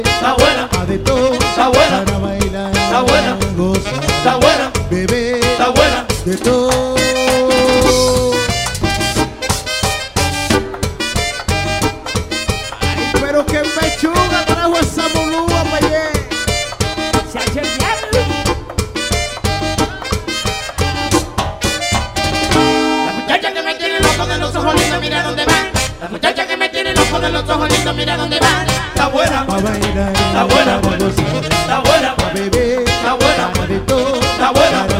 Música tropical, Latin